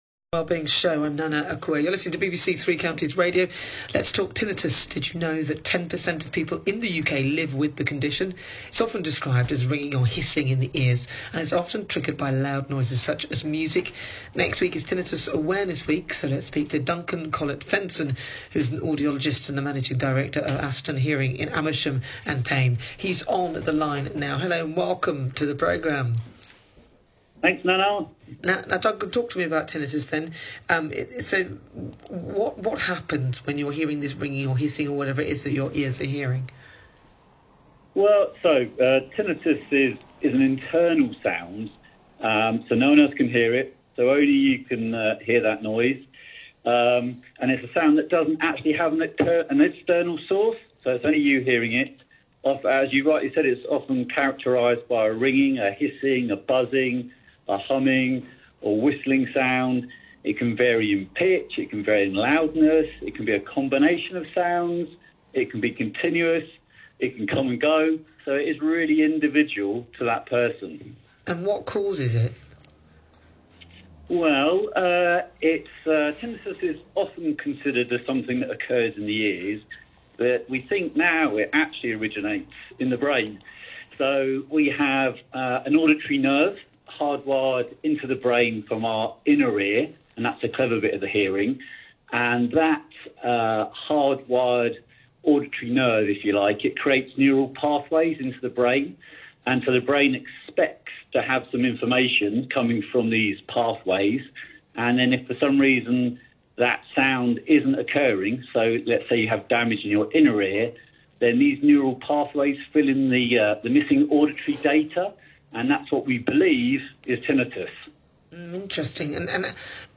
spoke to Nana Akua on air at BBC Three Counties Radio about tinnitus